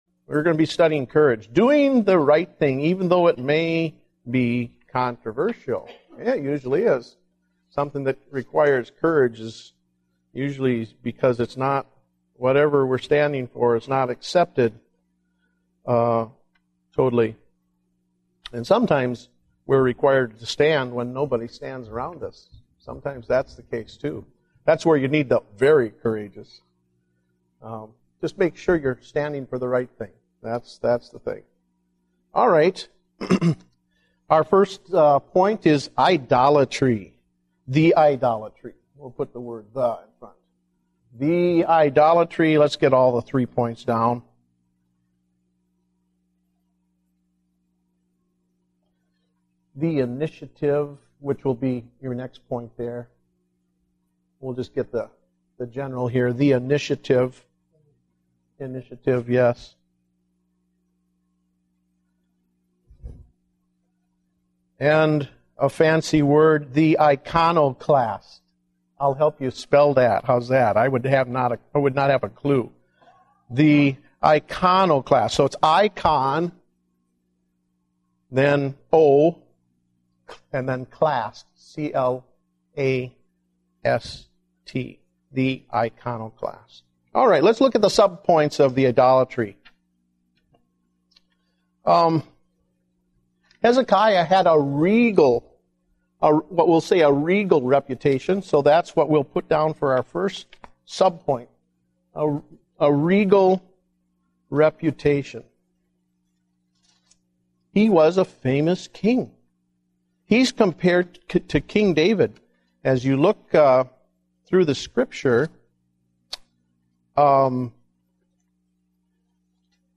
Date: February 7, 2010 (Adult Sunday School)